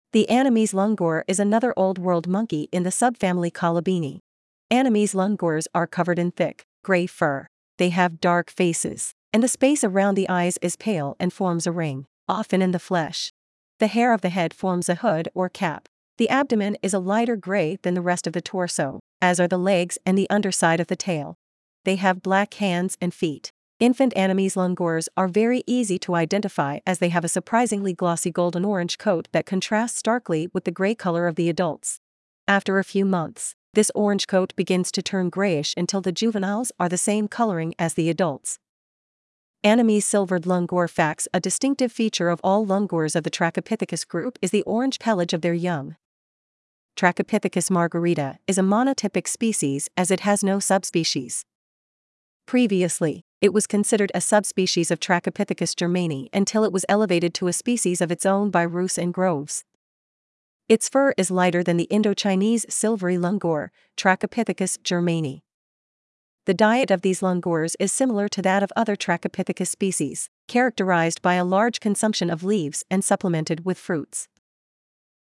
Annamese Silvered Langur
Trachypithecus-margarita.mp3